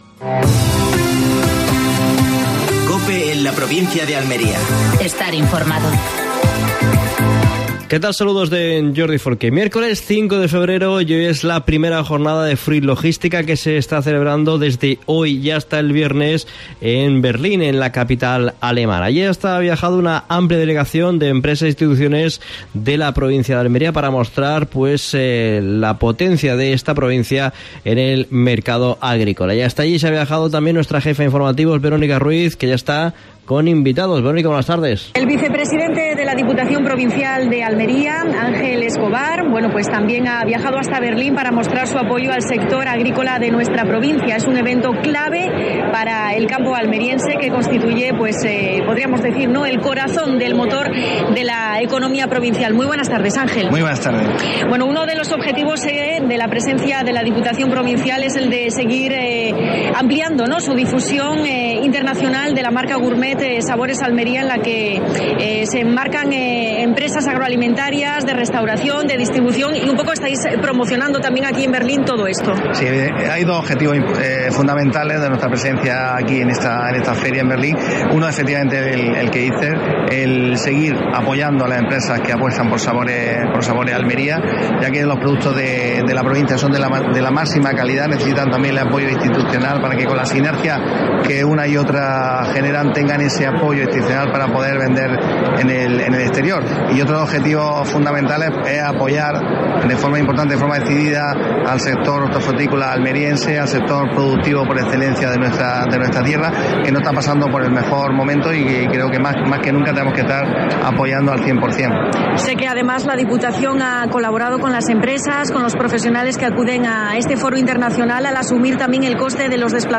Comienza Fruit Logística en Berlín. Entrevista a Ángel Escobar, vicepresidente de la Diputación Provincial de Almería.